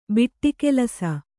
♪ biṭṭi kelasa